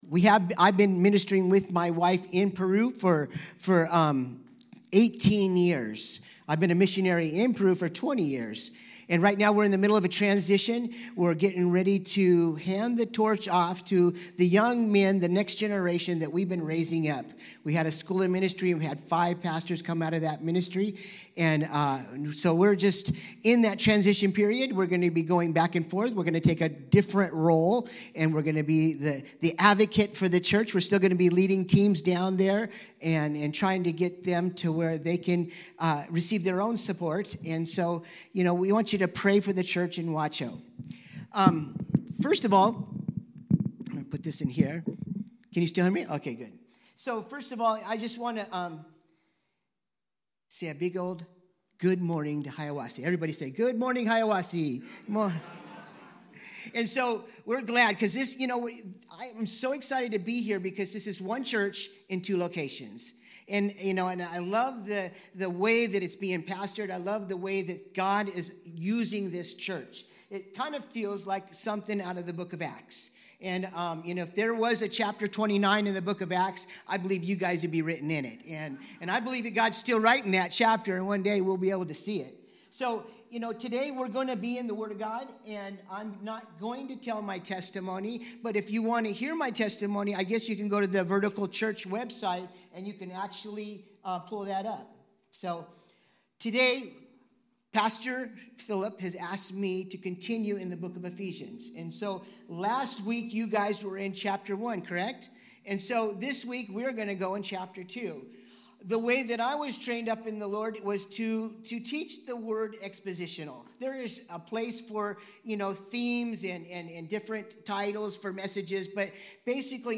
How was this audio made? This Sunday, we walked through Ephesians 2 together, and he showed us what scripture says about our past, present, and future, when we give God our whole heart. Give this message a listen to hear more on this topic on freedom in Christ!